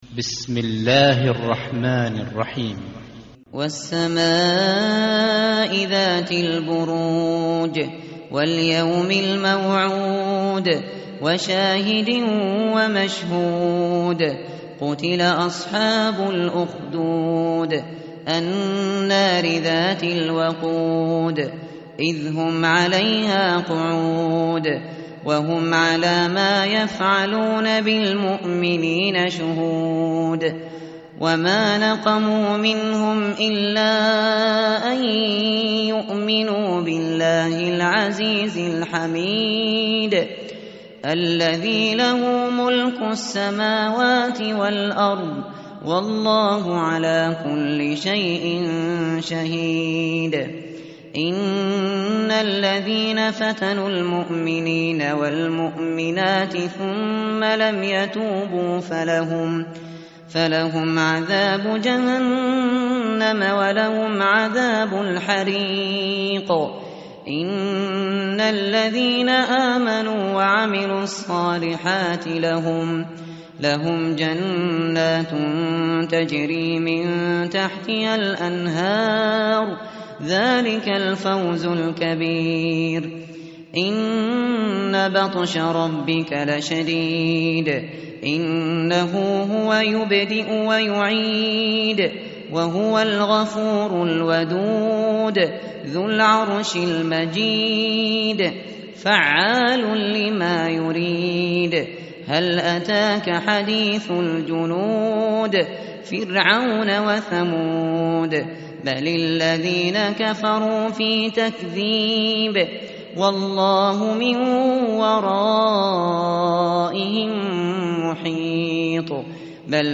tartil_shateri_page_590.mp3